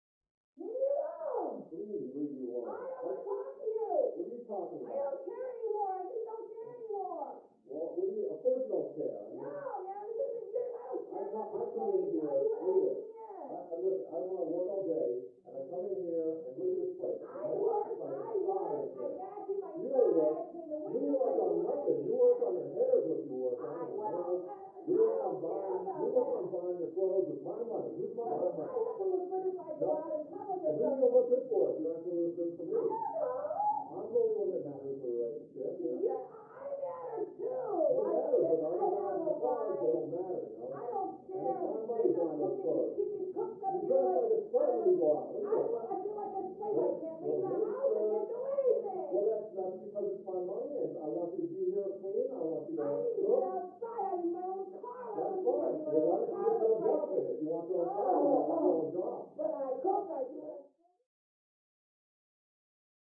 Argue; Couple Yelling At Each Other, Through Thick Wall.